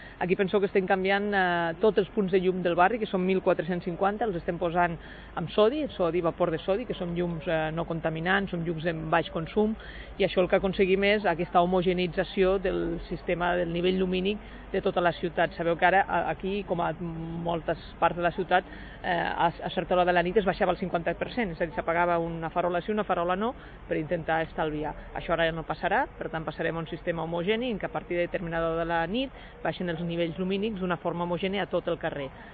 tall-de-veu-de-la-1a-tinent-dalcalde-i-regidora-durbanisme-marta-camps